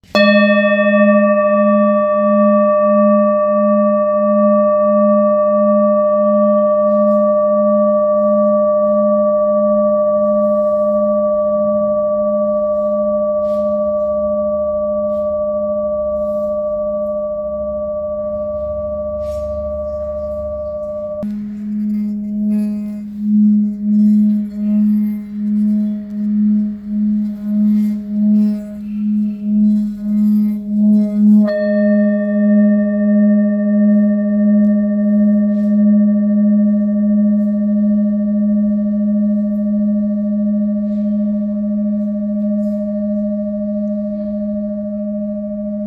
Full Moon Bowl, Buddhist Hand Beaten, Moon Carved, Antique Finishing, Note G 200 Hz
Material Seven Bronze Metal
This is a Himalayas handmade full moon singing bowl. The full moon bowl is used in meditation for healing and relaxation sound therapy.